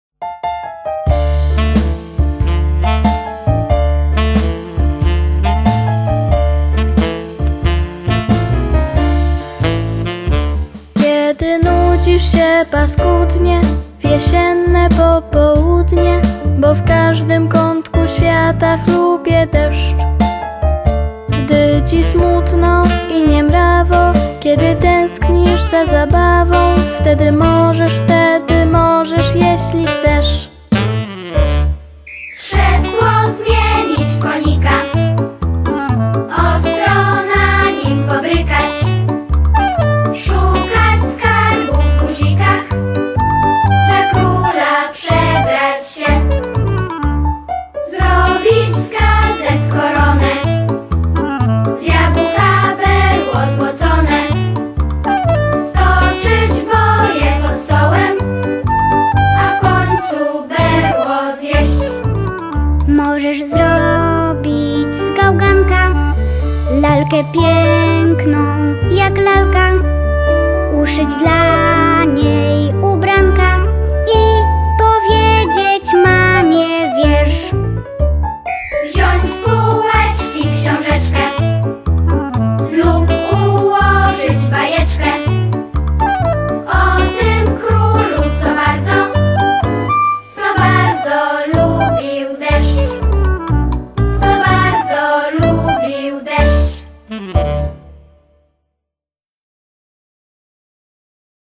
Jesienne popołudnie (wersja wokalno-instrumentalna)